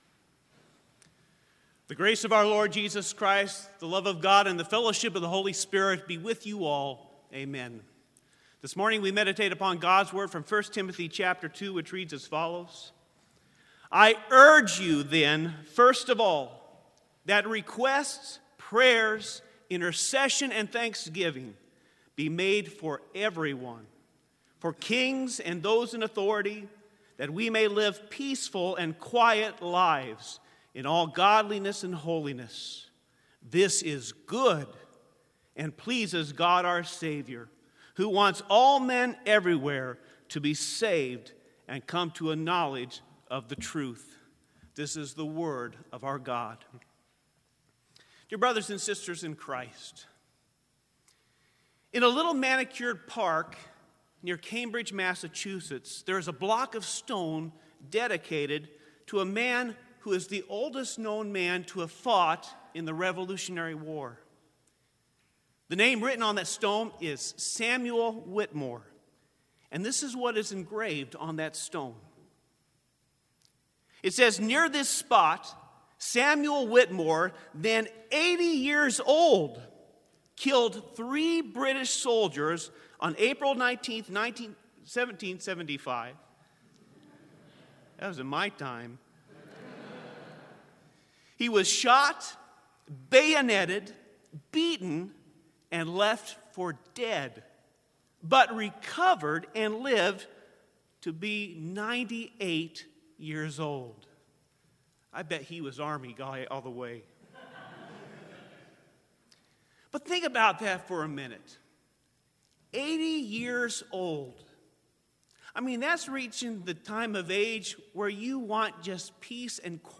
Complete service audio for Special - ELS Military Monument Chapel